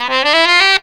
COOL SAX 11.wav